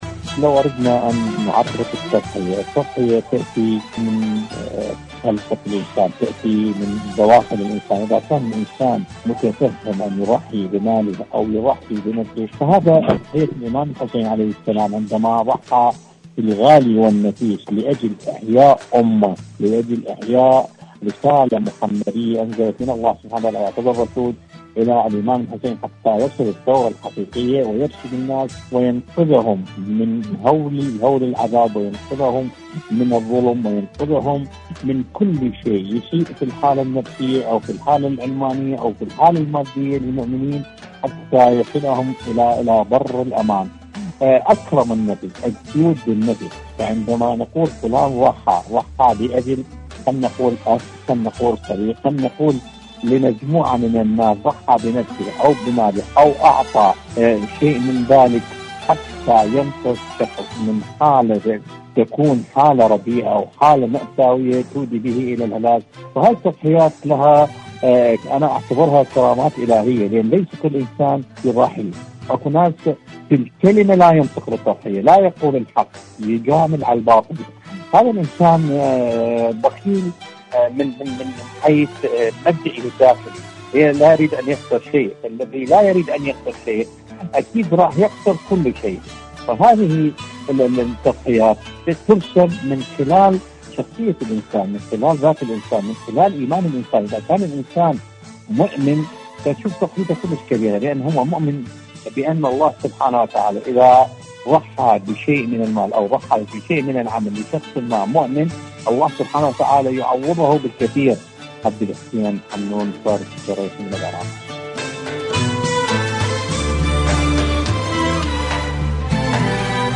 مشاركة هاتفية مشاركة صوتية